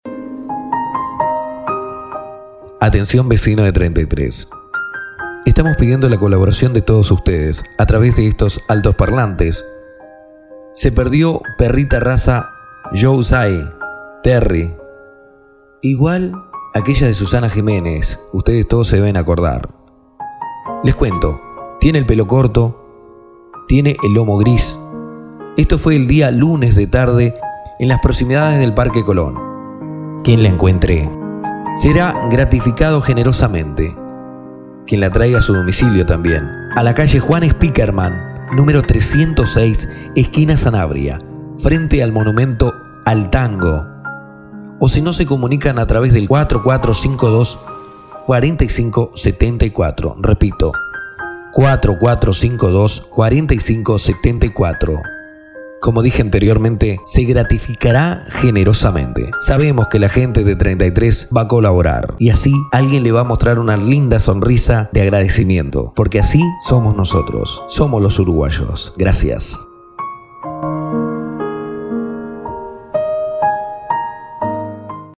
LOCUCIÓN
Espanhol - Castelhano (Espanha)